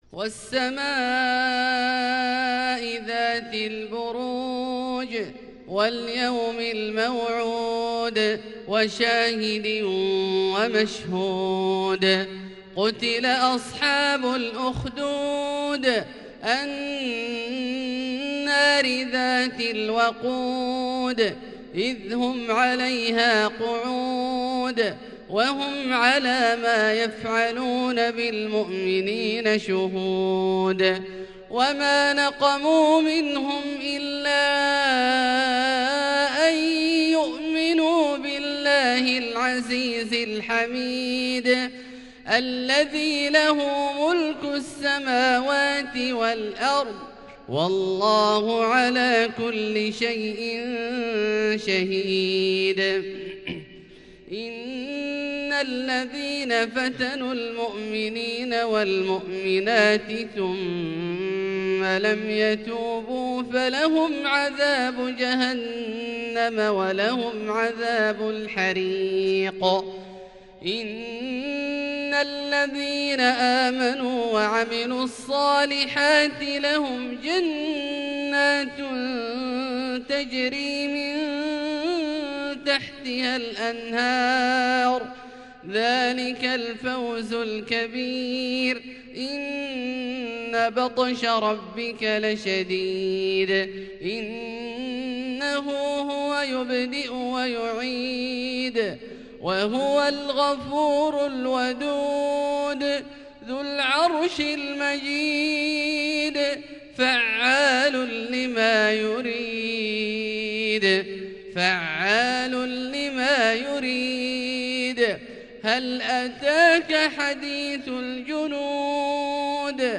فجر الخميس 8-6-1442 هــ سورتي البروج و الطارق > ١٤٤٢ هـ > الفروض - تلاوات عبدالله الجهني